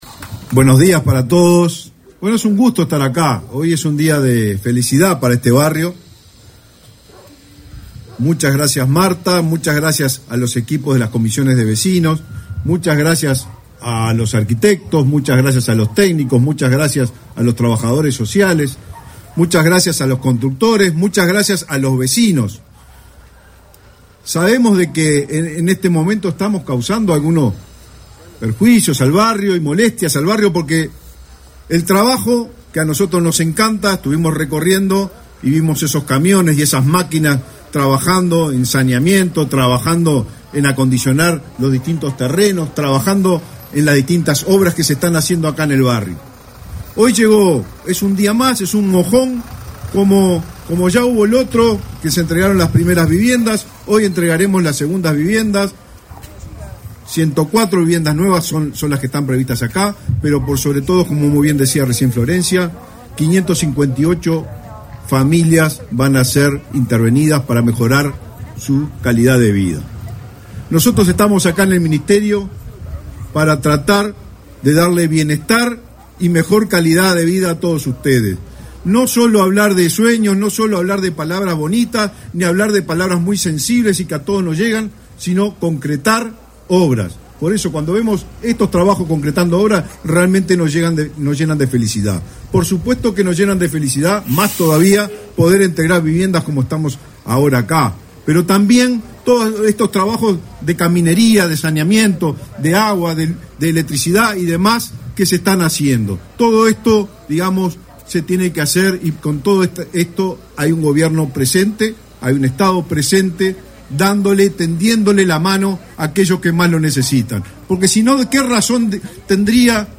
Acto de entrega de viviendas del Plan Avanzar
Participaron del evento el ministro Raúl Lozano, y la directora nacional de Integración Social y Urbana, Florencia Arbeleche.